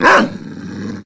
bdog_attack_5.ogg